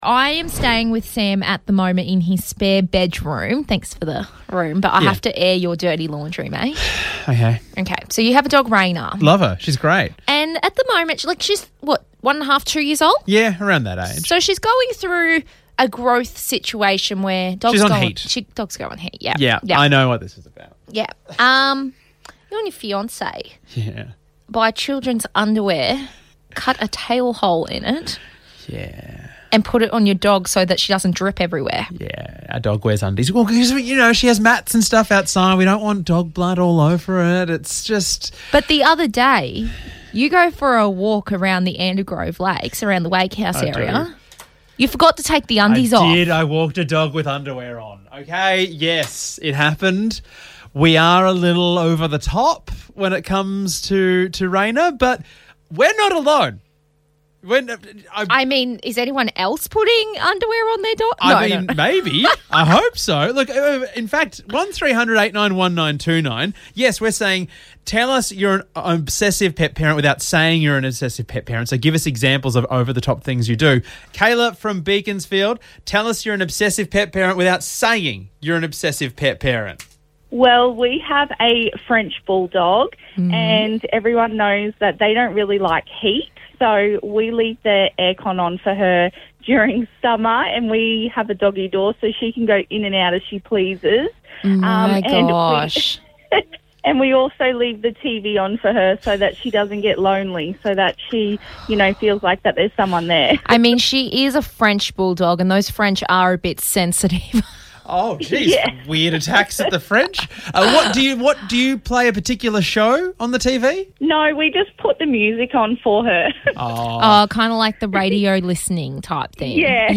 We through it to the phones and found out what you do for your pet!